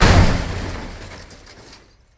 Hammer.wav